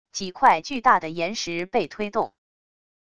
几块巨大的岩石被推动wav音频